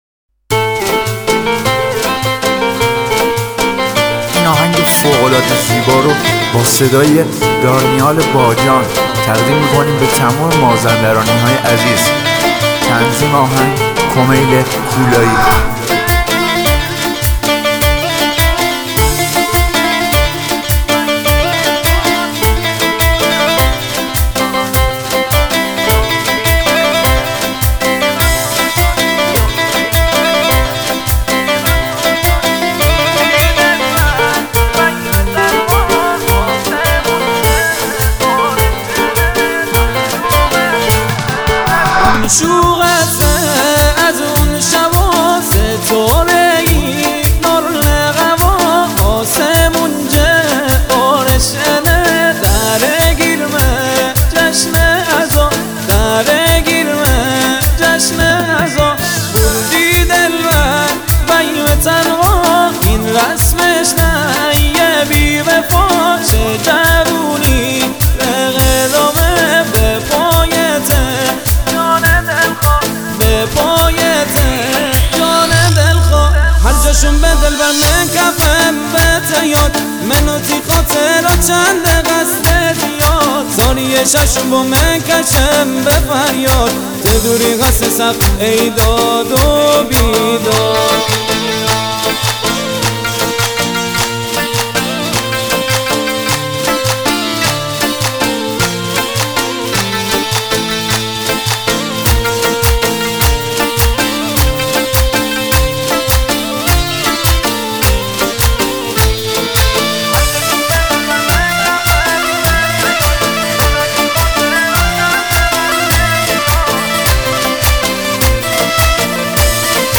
آهنگ مازندرانی